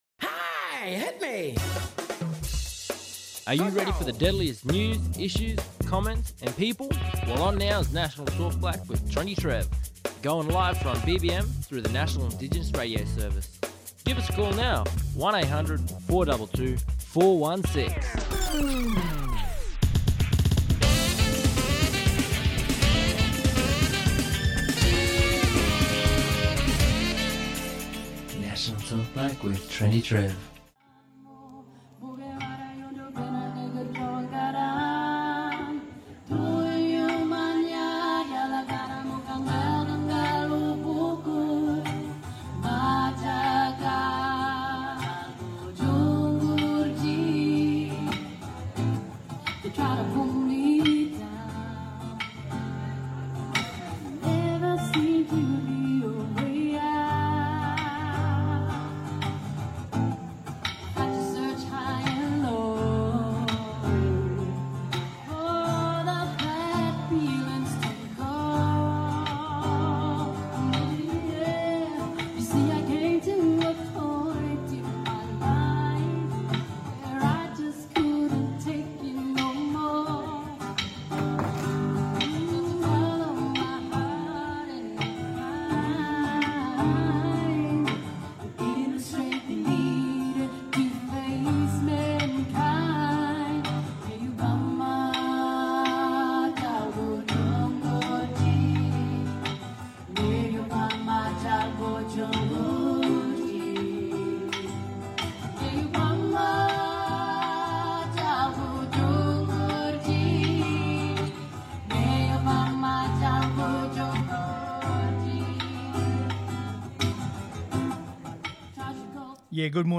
Commissioner Anne Hollonds, Australian Human Rights Commission National Children’s Commissioner, talking about the Commissioners urging the NT Government to reconsider new child justice laws.